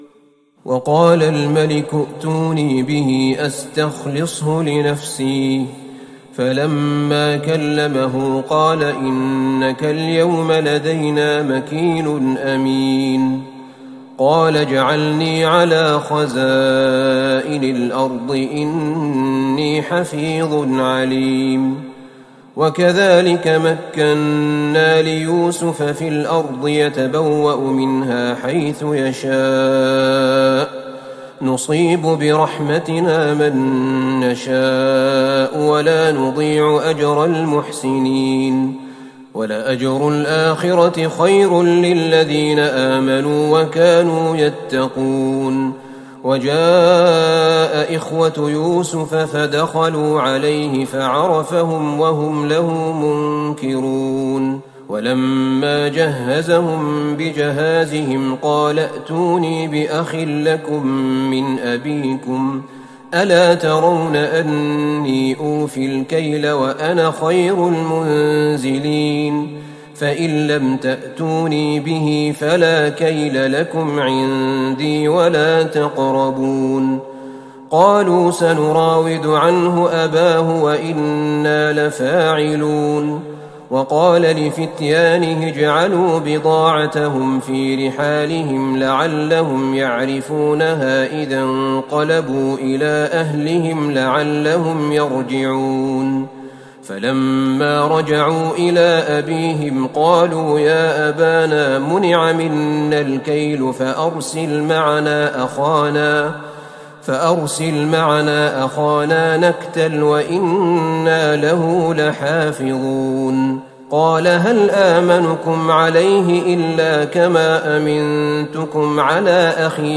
ليلة ١٧ رمضان ١٤٤١هـ من سورة يوسف { ٥٤-١١١ } والرعد { ١-١١ } > تراويح الحرم النبوي عام 1441 🕌 > التراويح - تلاوات الحرمين